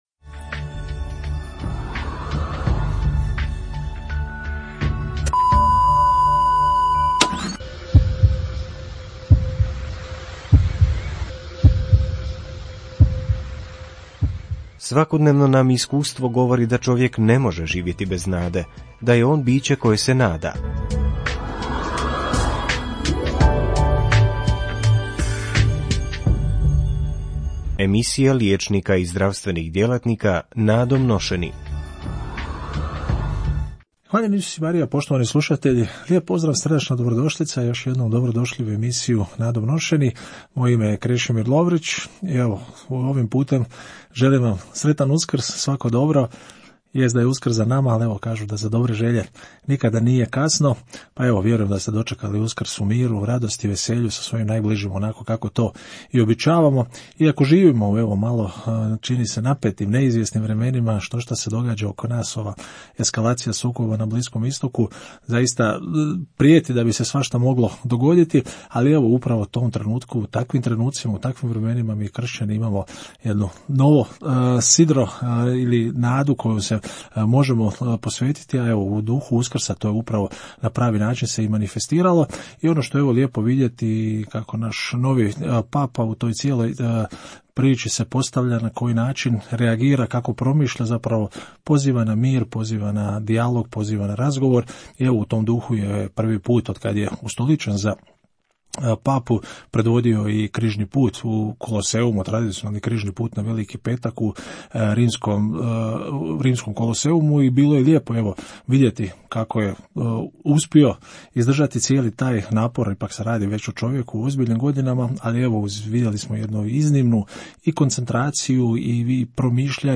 Prenosimo cjelovitu emisiju Radio Marije “Nadom nošeni”.